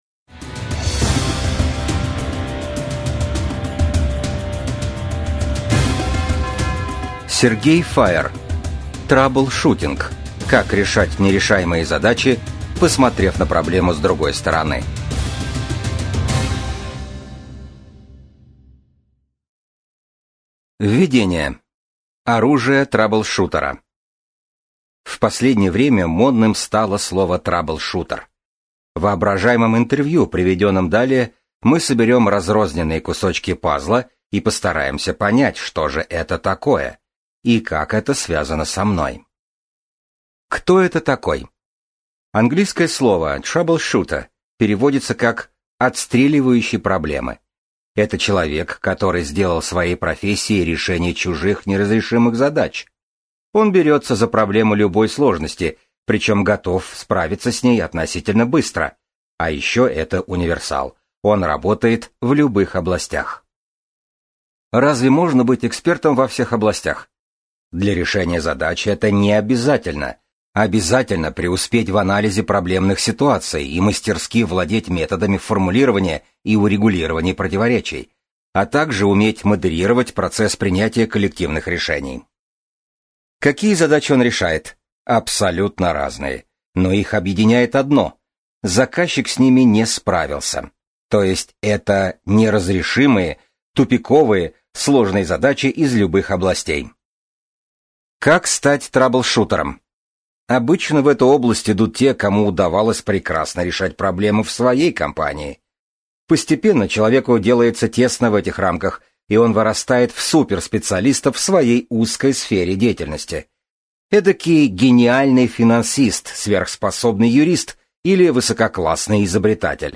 Аудиокнига Траблшутинг: Как решать нерешаемые задачи, посмотрев на проблему с другой стороны | Библиотека аудиокниг